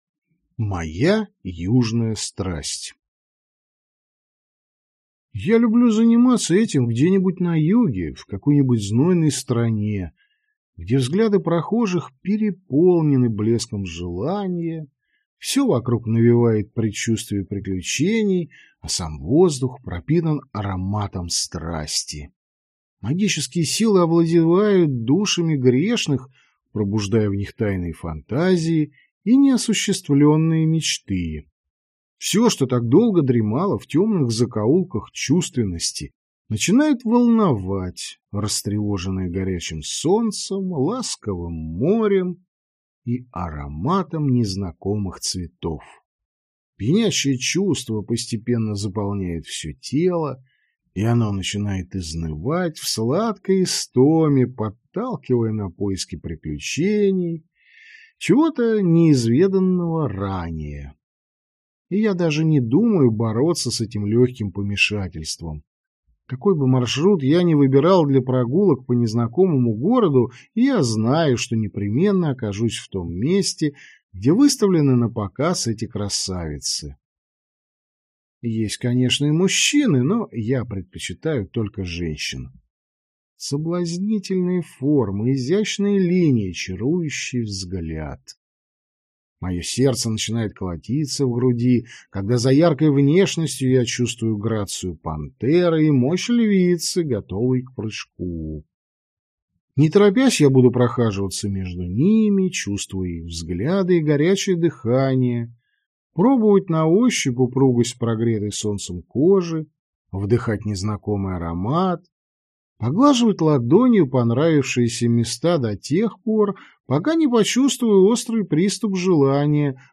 Аудиокнига Ключи от дома (сборник) | Библиотека аудиокниг